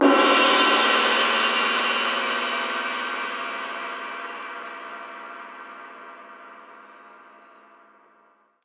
Zen Crash Mallet.wav